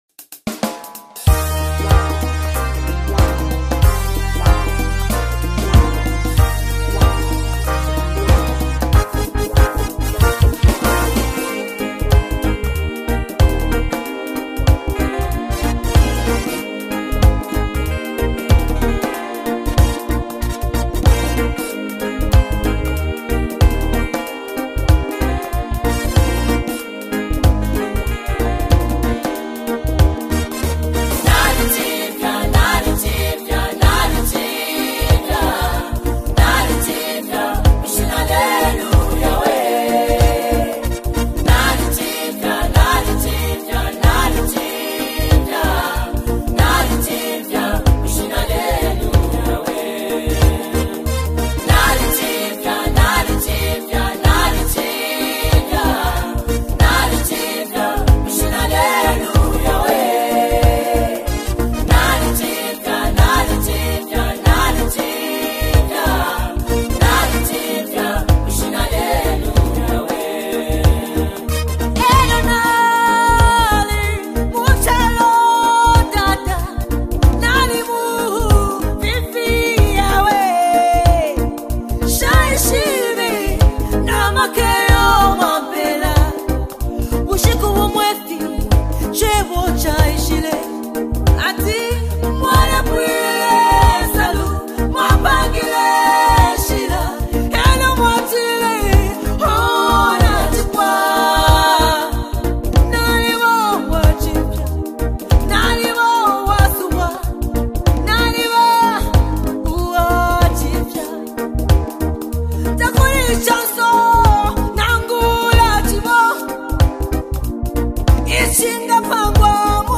Best Classic Worship Song
uplifting melodies